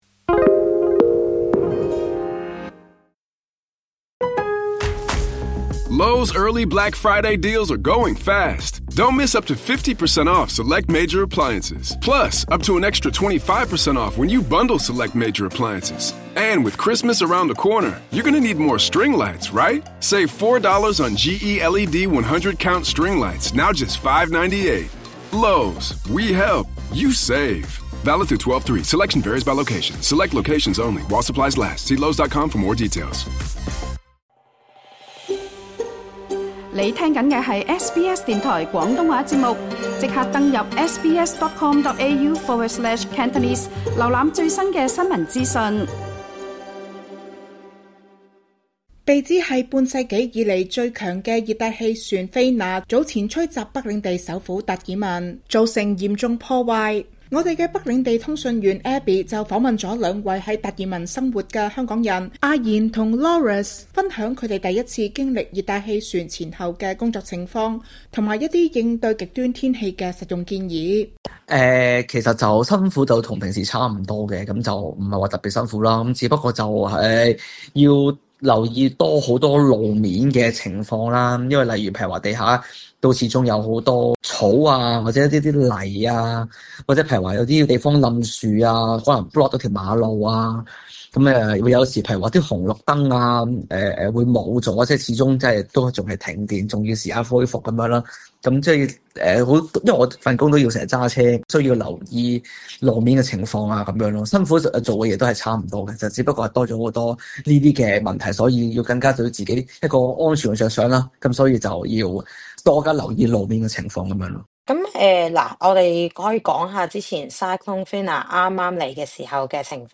被指為半世紀最強的熱帶氣旋「菲娜」（Fina）席捲達爾文，帶來狂風暴雨，大量樹木倒塌並造成逾萬戶停電。兩名在當地生活的港人分享風暴前後的工作經歷，包括垃圾回收及物管行業在極端天氣下面對的挑戰。